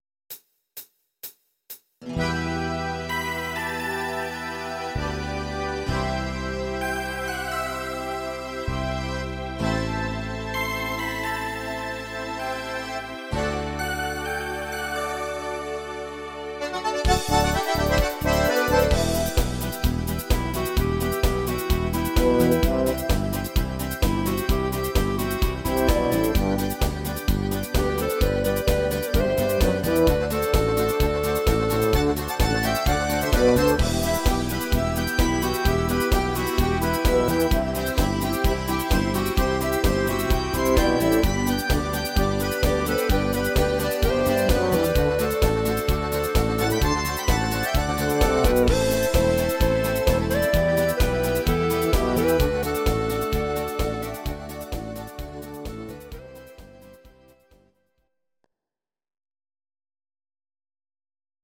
These are MP3 versions of our MIDI file catalogue.
Please note: no vocals and no karaoke included.
Your-Mix: Volkstï¿½mlich (1262)